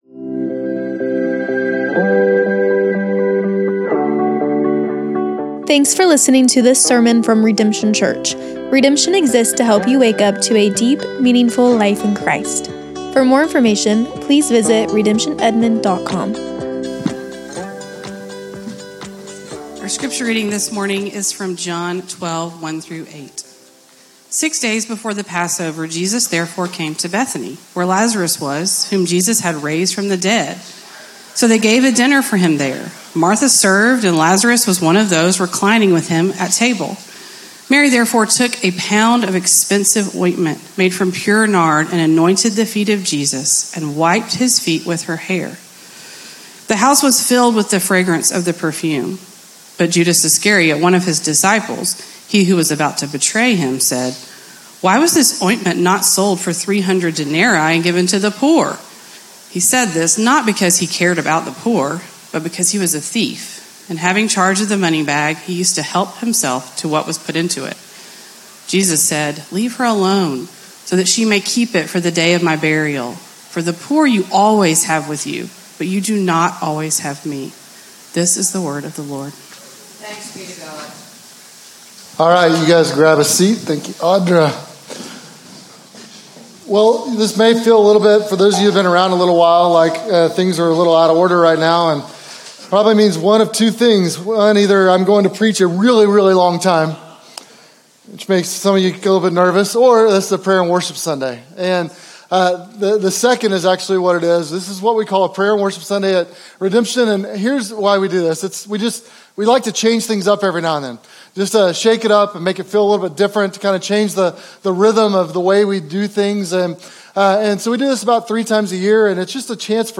This is a Prayer & Worship Sunday!